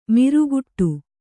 ♪ miruguguṭṭu